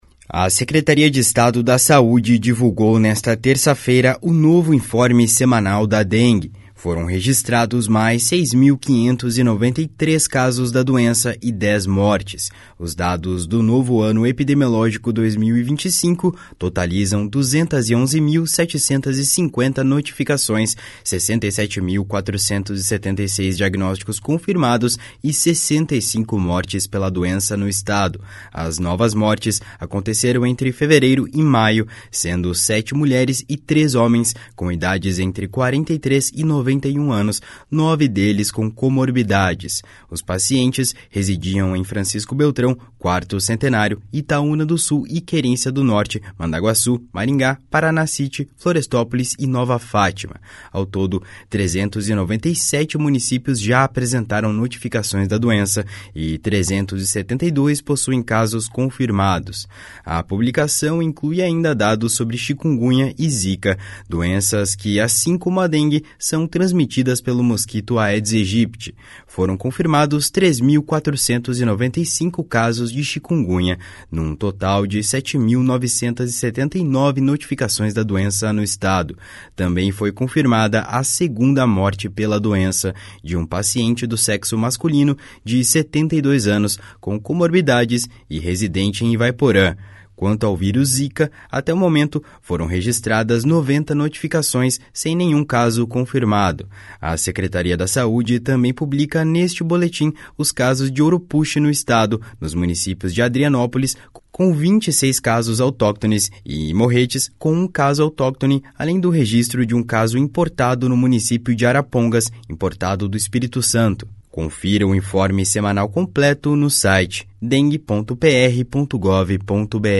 BOLETIM SEMANAL DA DENGUE.mp3